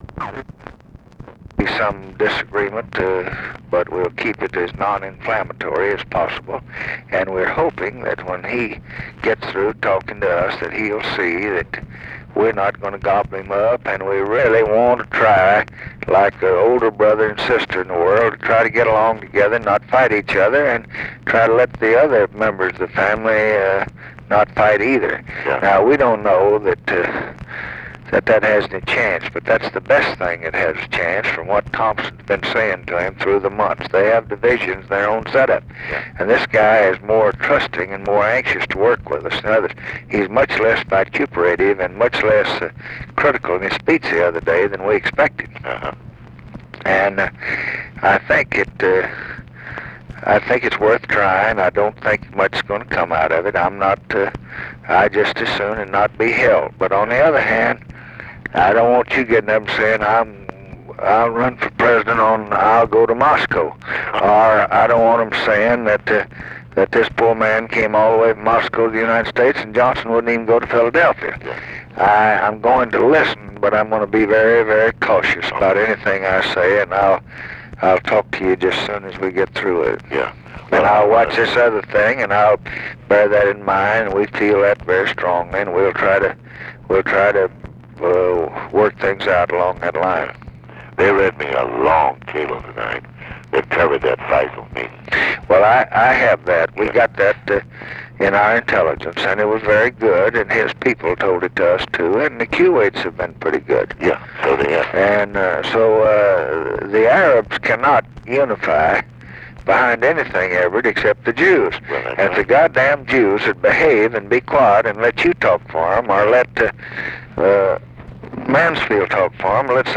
Conversation with EVERETT DIRKSEN, June 23, 1967
Secret White House Tapes